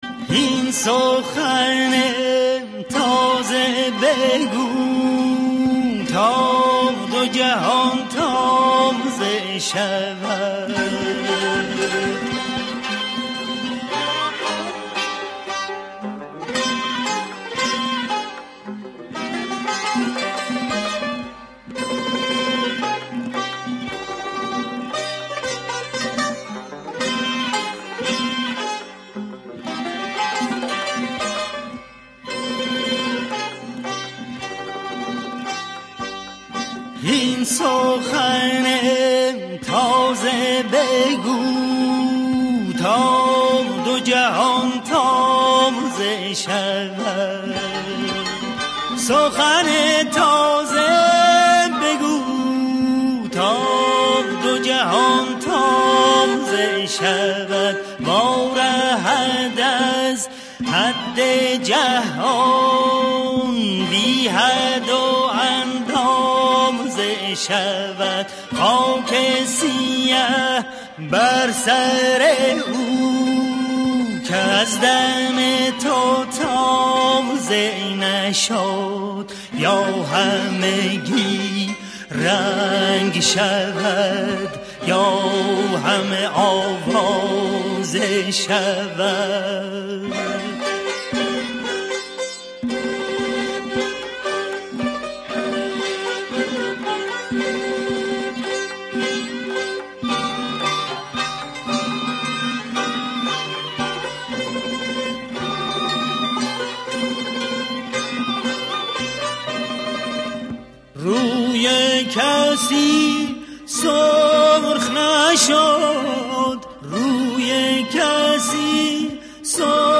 دانلود فایلدانلود تصنیف